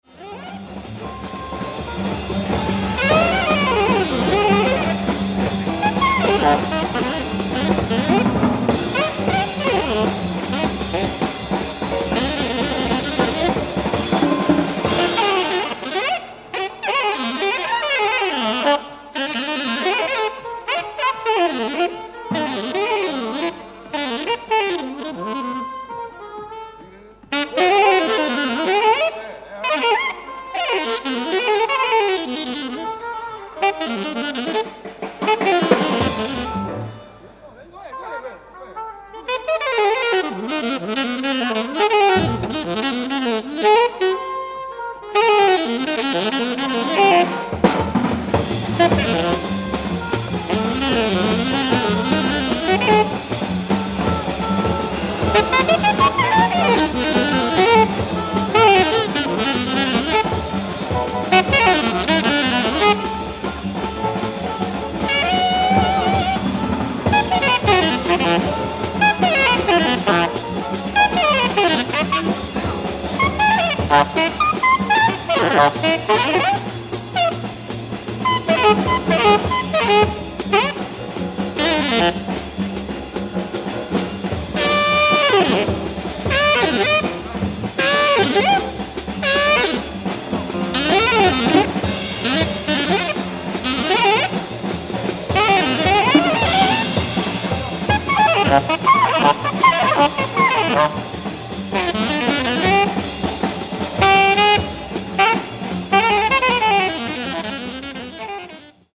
ライブ・アット・コンセルトヘルボウ、アムステルダム、オランダ 04/10/1964
海外マニアのリマスター音源盤！！
※試聴用に実際より音質を落としています。